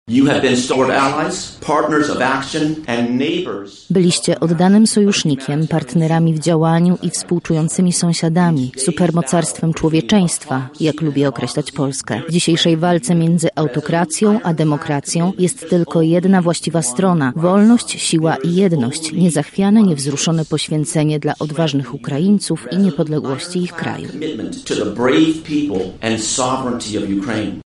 Dziś mury Uniwersytetu Marii Curie-Skłodowskiej odwiedził Mark Brzezinski, Ambasador Stanów Zjednoczonych w Polsce.
Dla zebranych poprowadził wykład o współczesnych stosunkach miedzy narodami. W jego wystąpieniu nie mogło zabraknąć tematu wojny za naszą wschodnią granicą.
Polska i Polacy wszystkich pokoleń, wy jesteście przykładem dla całego świata do naśladowania w tym roku – mówi Mark Brzezinski, Ambasador Stanów Zjednoczonych w Polsce: